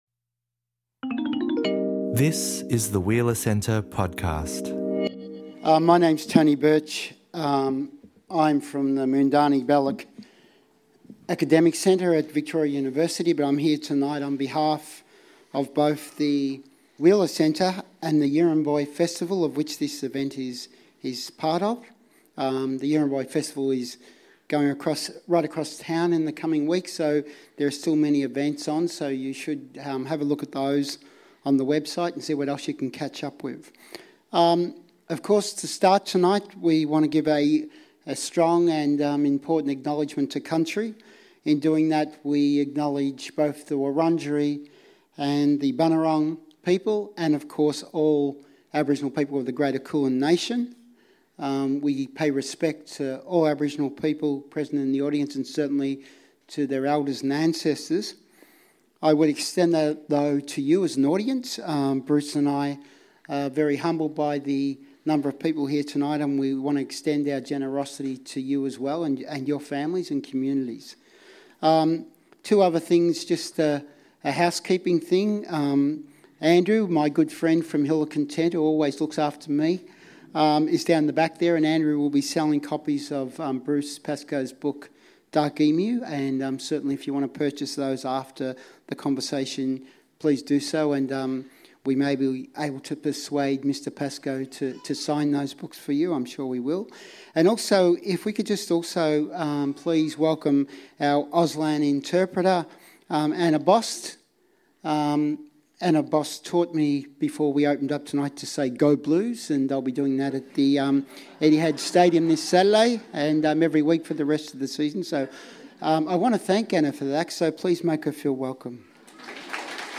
Dark Emu: Bruce Pascoe and Tony Birch in Conversation